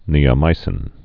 (nēə-mīsĭn)